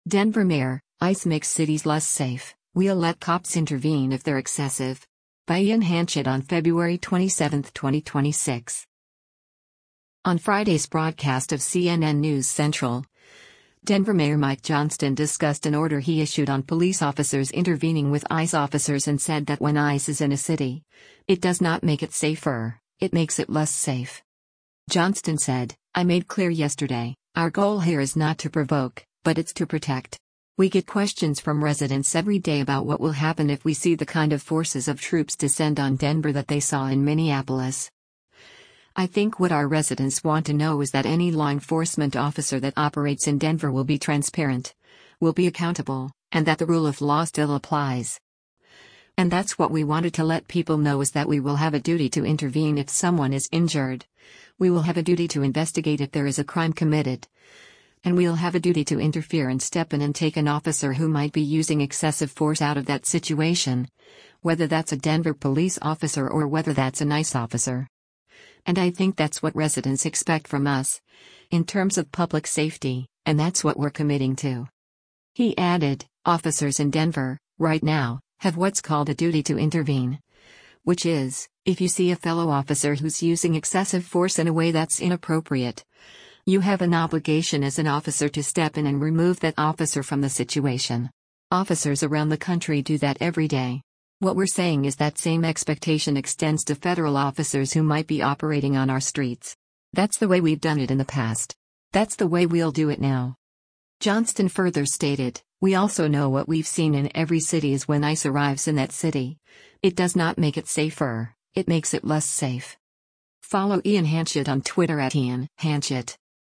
On Friday’s broadcast of “CNN News Central,” Denver Mayor Mike Johnston discussed an order he issued on police officers intervening with ICE officers and said that when ICE is in a city, it “does not make it safer, it makes it less safe.”